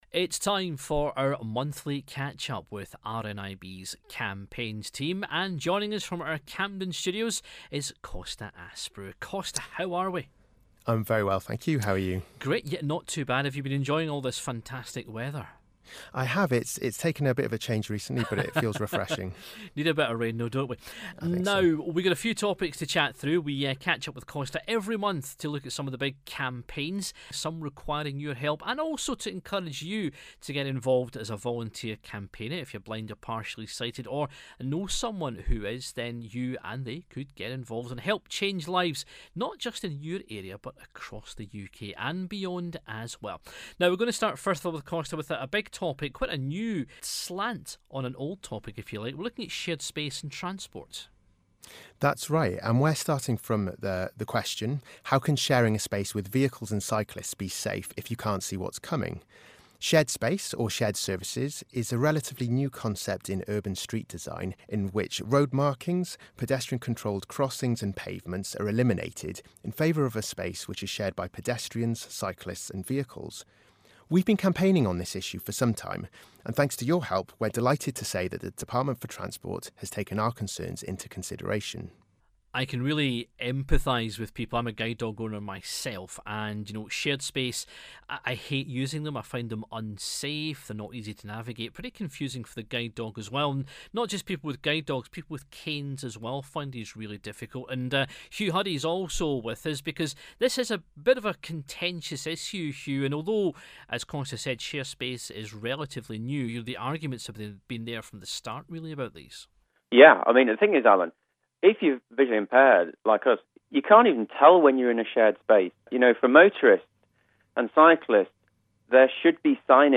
They discuss the current hot topics and this months has a surprise from the Department For Transport.